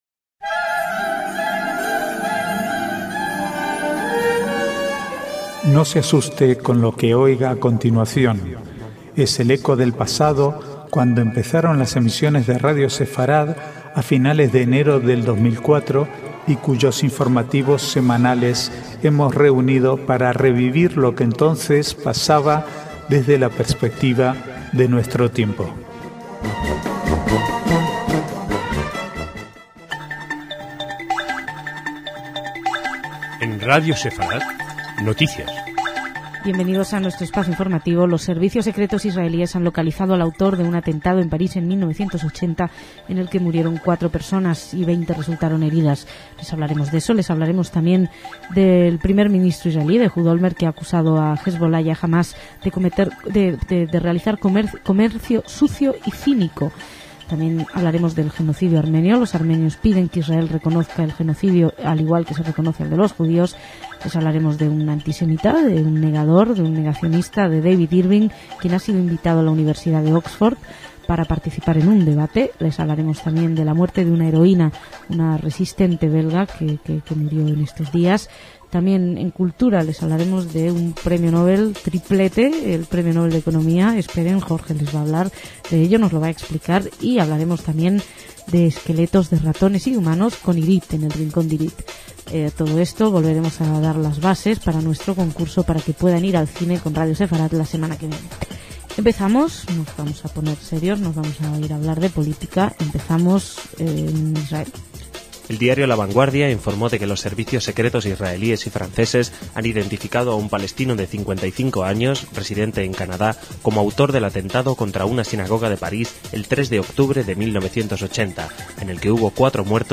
Archivo de noticias del 17 al 19/10/2007